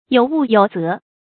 有物有则 yǒu wù yǒu zé
有物有则发音